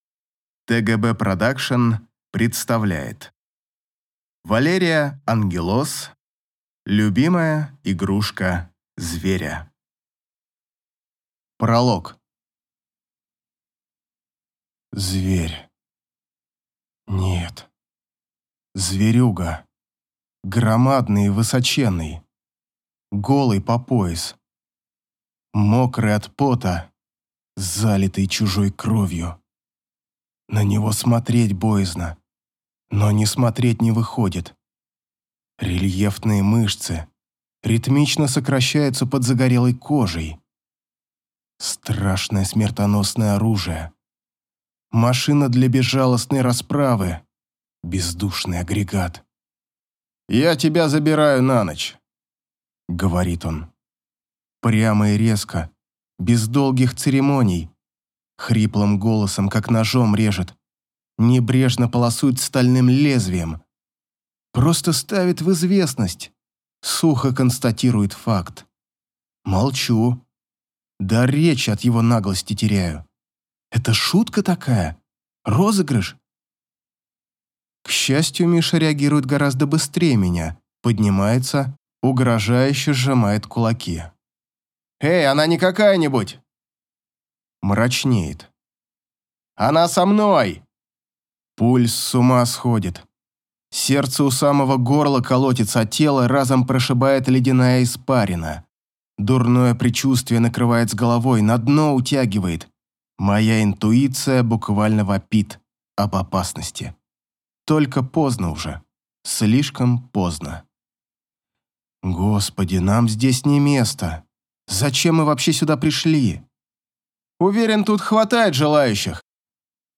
Аудиокнига Любимая игрушка Зверя | Библиотека аудиокниг